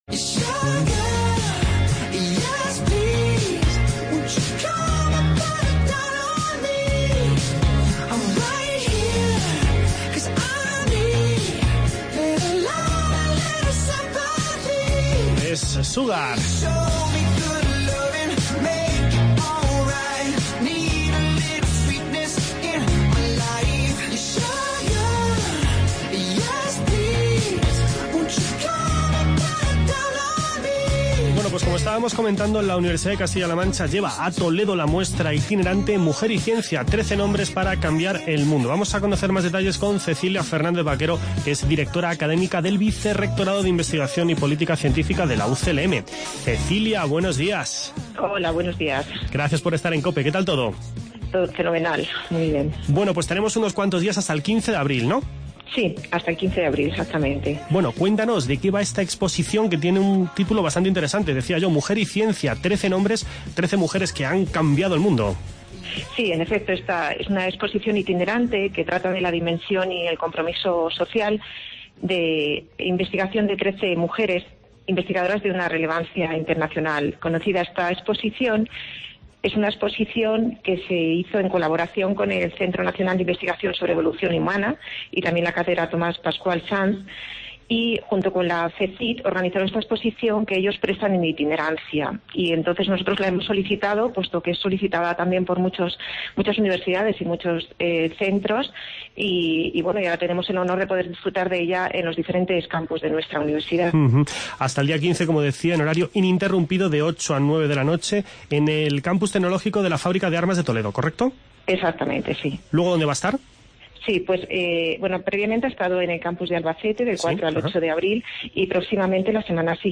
Por otro lado, les contamos que la Junta de Comunidades ha llegado a un acuerdo con las organizaciones profesionales agrarias y las Cooperativas Agroalimentarias con relación a la elaboración de un Plan Estratégico de Agricultura Ecológica para Castilla-La Mancha para el período 2016-2020. Nos explica todos los detalles el consejero de Agricultura, Francisco Martínez Arroyo.